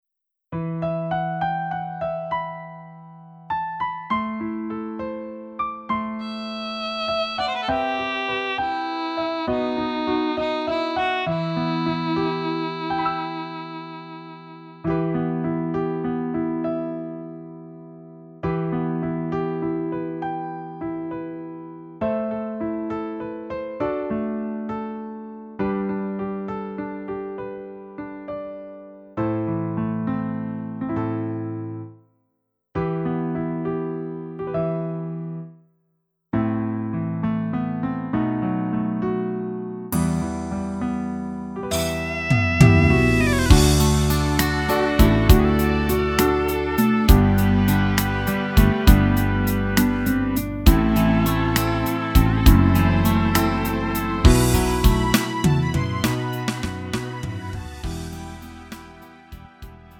미리듣기
음정 -1키
장르 가요 구분 Lite MR